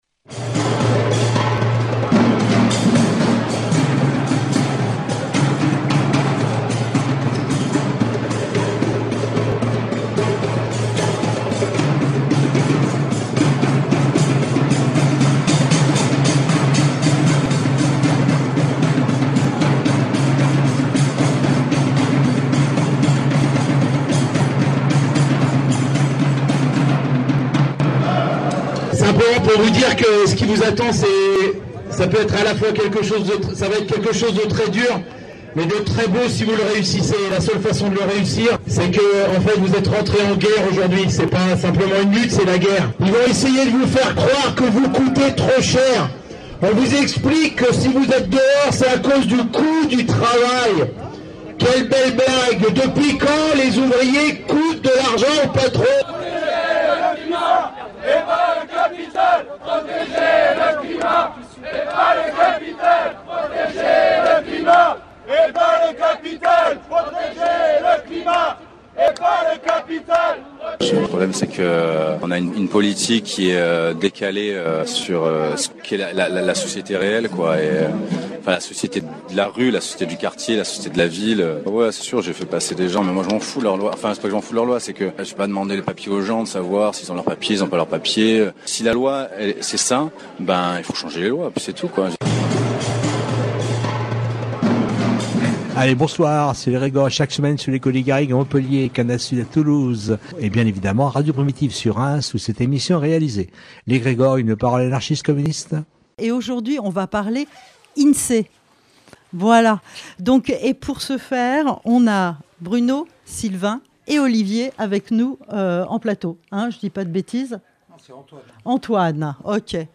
Dans cette émission, nous recevons des agents syndiqués de l’Institut National de la Statistique et des Études Économiques (Insee) avec lesquels nous nous entretenons de la répression syndicale au sein de l’institution.